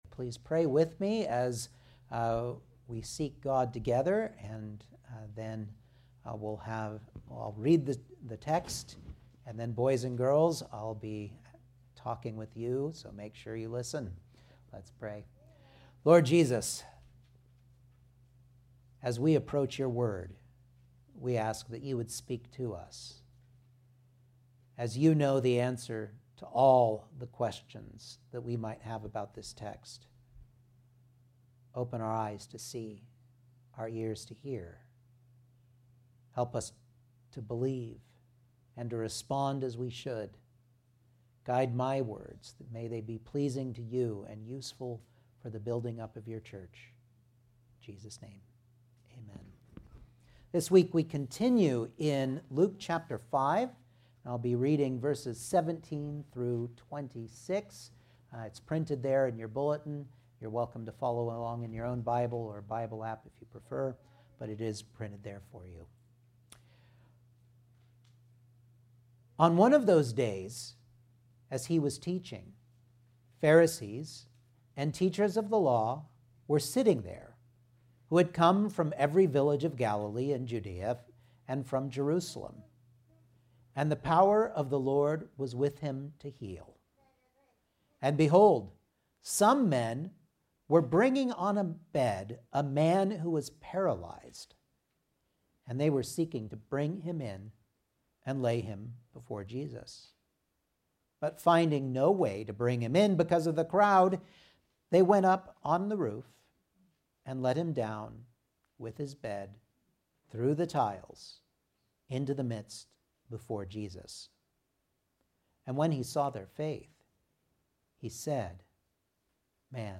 Passage: Luke 5:17-26 Service Type: Sunday Morning Outline